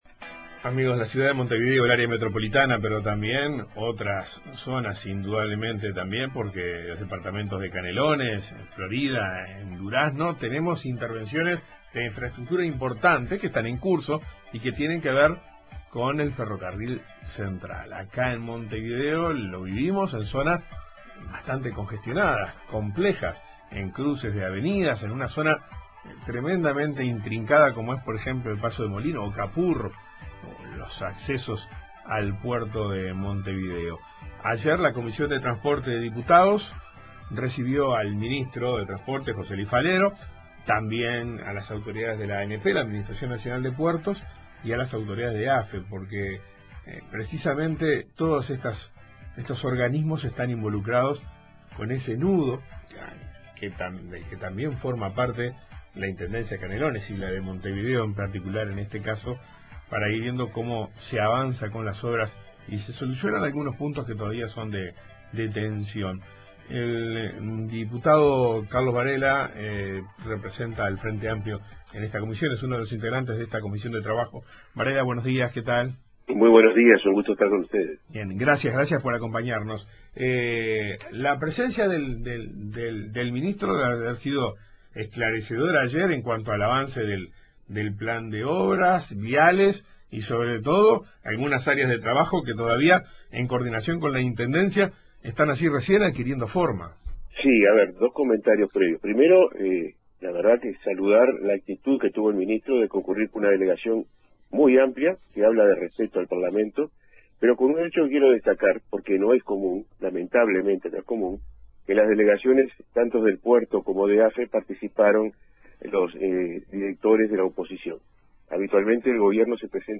El diputado del Frente Amplio Carlos Varela, integrante de la comisión de Transporte de Diputados, comentó en Informativo Uruguay que según lo conversado con el ministro, el nuevo cronograma ubica la culminación de obras para mediados del año próximo, aunque al momento de la comparecencia del ministro a comisión, estaba pendiente una reunión de las autoridades con las empresas involucradas en el proyecto.